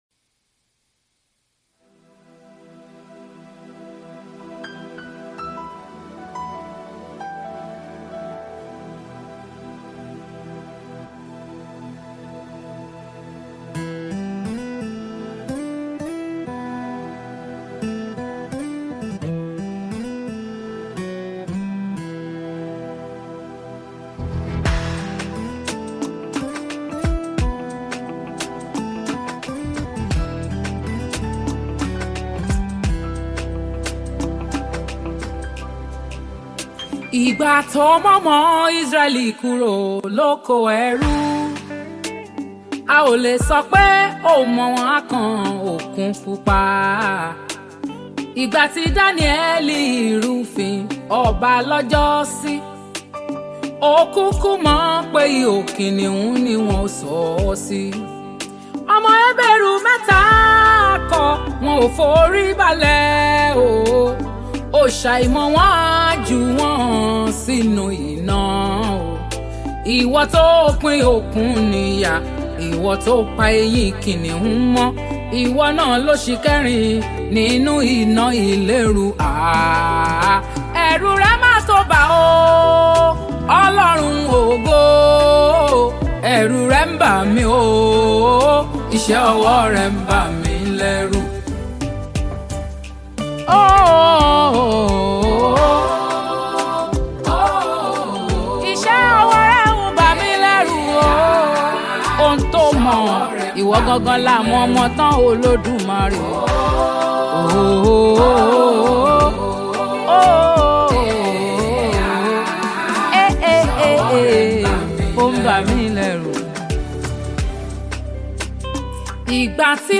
Gospel Music
Genre: Afrobeats